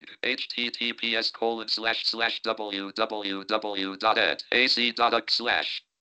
• swapped a written example of screen reader output for an audio clip
Hearing a screen reader in action made the point much more clearly than words alone.
The screen reader will read out the URL, which sounds like this: “H T T P S colon slash slash W W W dot E D dot A C dot U K slash”.
JAWS-audio-Articulate-1.mp3